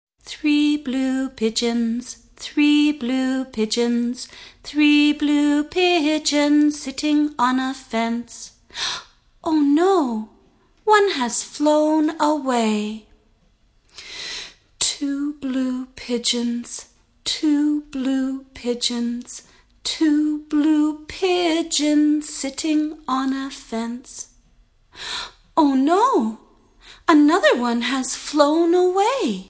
Children's Fingerplay Song